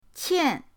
qian4.mp3